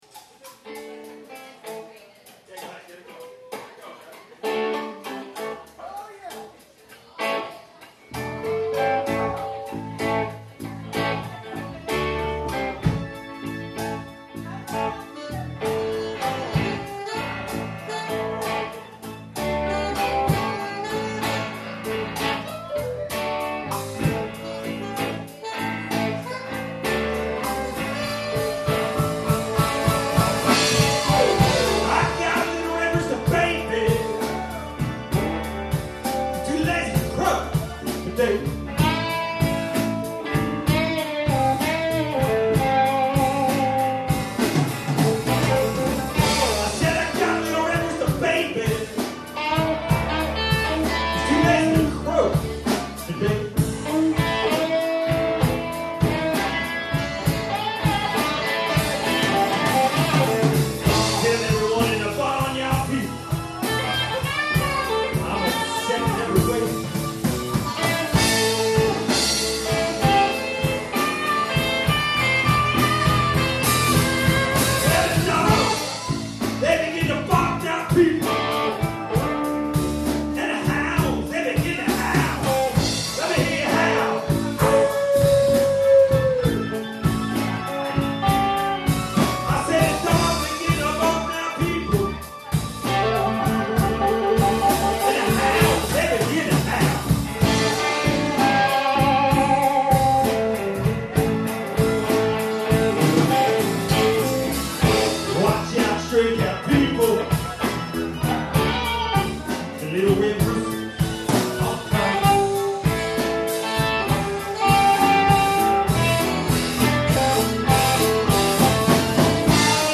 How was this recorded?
Live at The Fujiyama Sunset Lounge Pottstown, PA